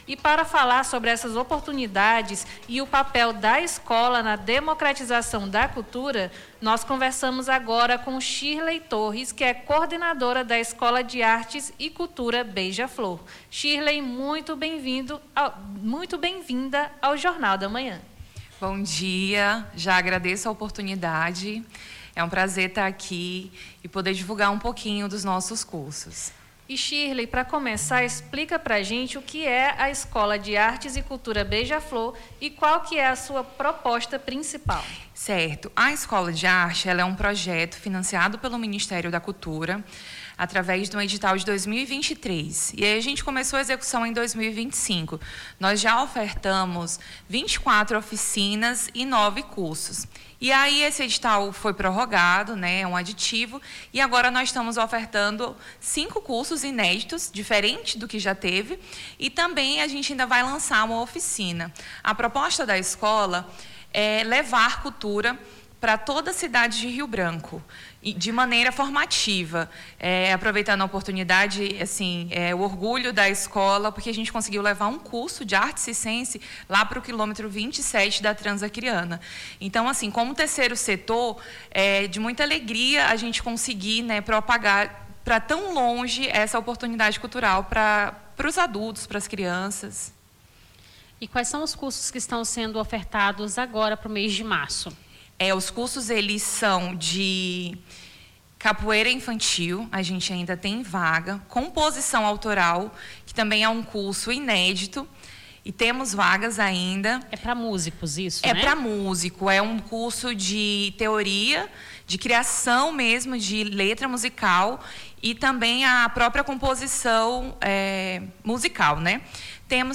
Baixar Esta Trilha Nome do Artista - CENSURA - ENTREVISTA INSCRIÇOES CURSOS ESCOLA BEIJA-FLOR - 27-02-26.mp3 Digite seu texto aqui...